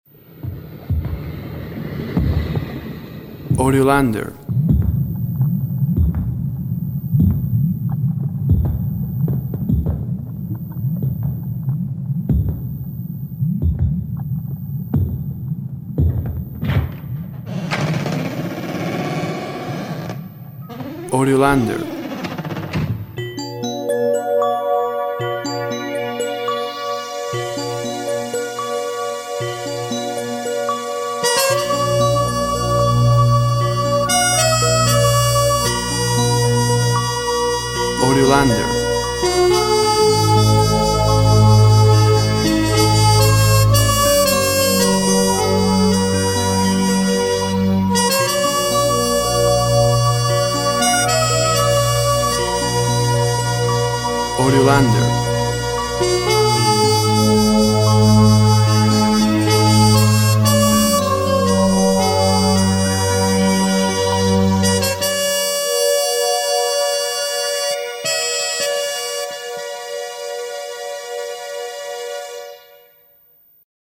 Musical film element of mystery.
WAV Sample Rate 16-Bit Stereo, 44.1 kHz
Tempo (BPM) 85